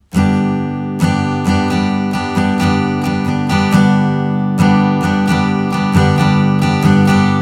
1. Schlagmuster für Gitarre